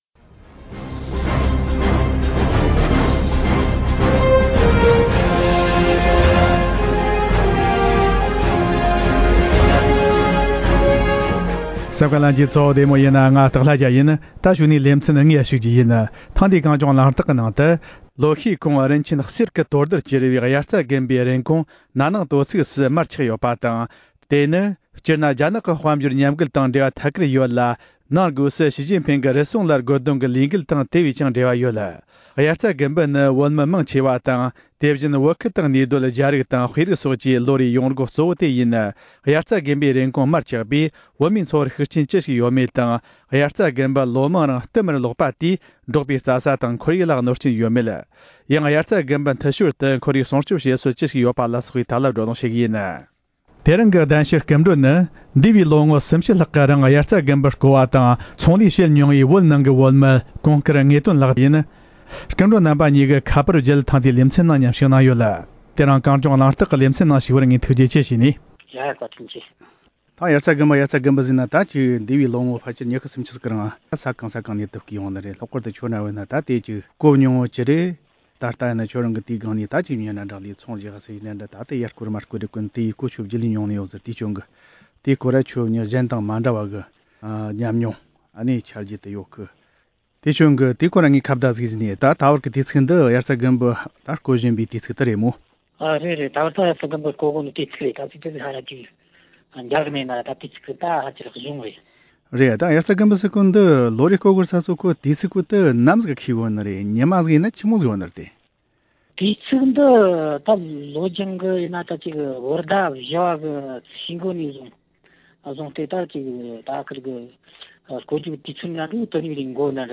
དབྱར་རྩྭ་དགུན་འབུ་རྐོ་སློག་བྱེད་སྟངས་དང་། དབྱར་རྩྭ་དགུན་འབུ་དང་བོད་མིའི་འཚོ་བའི་བར་གྱི་འབྲེལ་བ་དེ་བཞིན་འབྲོག་པའི་རྩྭ་ས་དང་ཁོར་ཡུག་ལ་གནོན་སྐྱོན་ཡོད་མེད་སོགས་ཀྱི་ཐད་གླེང་མོལ།